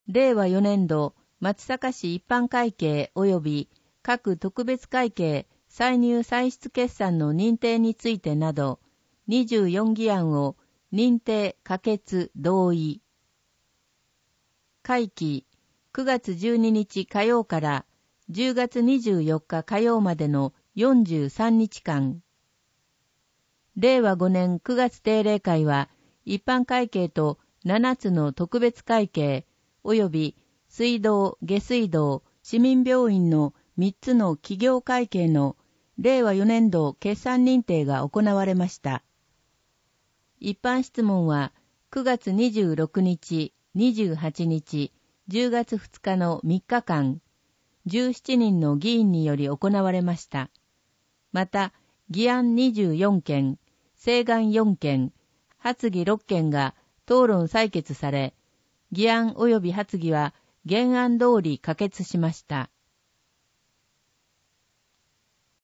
声の市議会だより
なお、この音声は「音訳グループまつさか＜外部リンク＞」の皆さんの協力で作成しています。